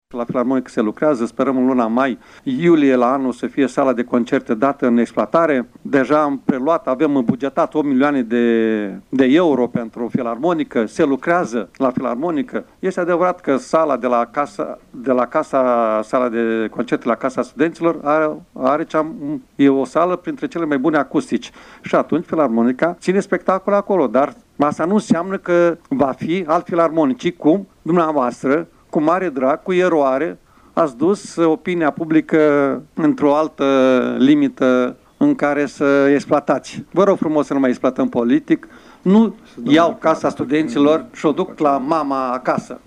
Preluarea clădirii Casei Studenţilor din Iaşi de către Consiliul Judeţean a născut astăzi dispute aprige în şedinţa forului condus de Maricel Popa.
De partea cealaltă, preşedintele Consiliului Judeţean Iaşi, Maricel Popa, a precizat că  nu se pune problema mutării sediului Filarmonicii deoarece clădirea administrativă va fi consolidată până în vara anului viitor.